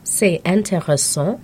Click each phrase to hear the pronunciation.